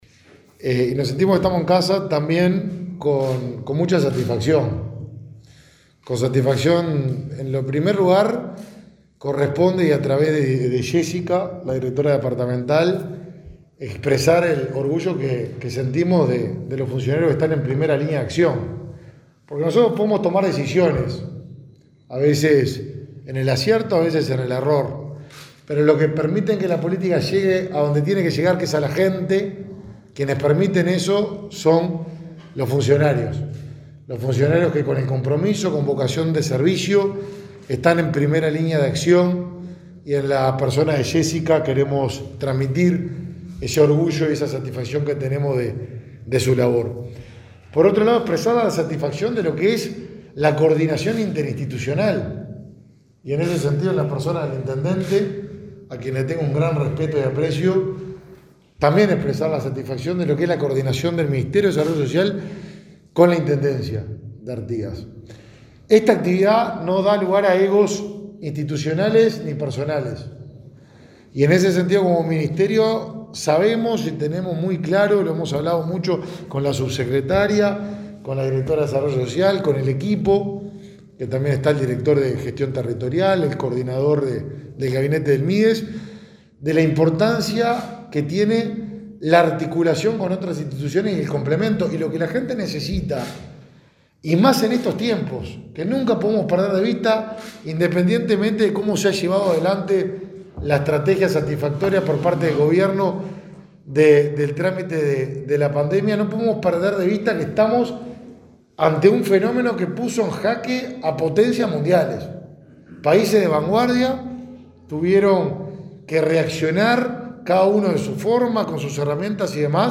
El ministro de Desarrollo Social, Martín Lema, brindó una conferencia de prensa en Artigas, en el marco de una serie de actividades que el secretario